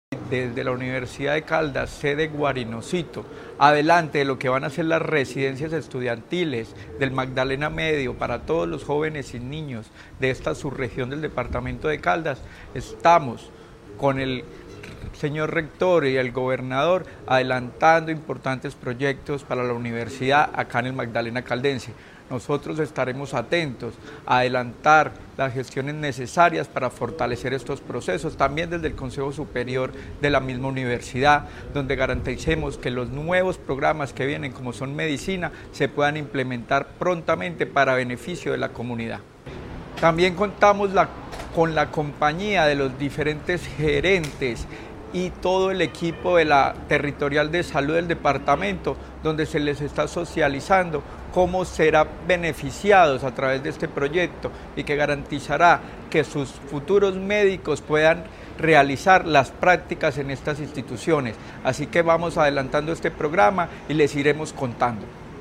Secretario de Educación de Caldas, Luis Herney Vargas Barrera.
Secretario-de-Educacion-Luis-Herney-Vargas-Barrera-universidad-en-La-Dorada-1.mp3